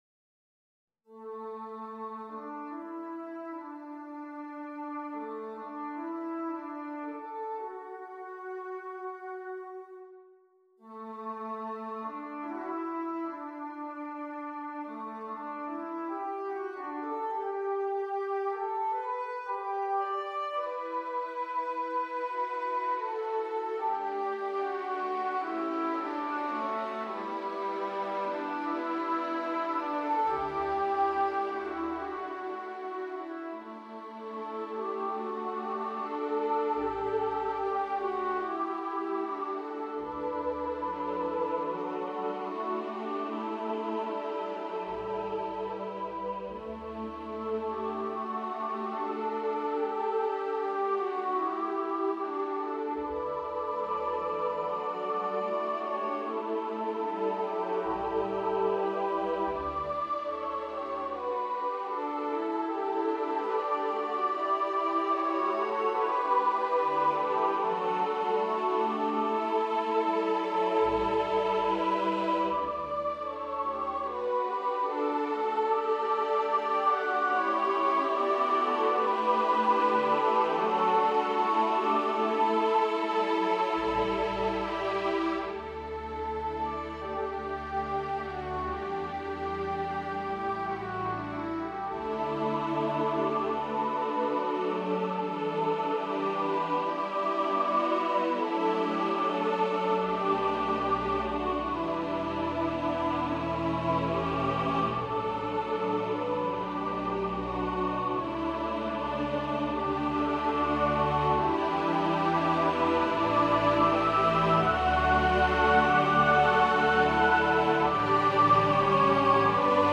for choir and orchestra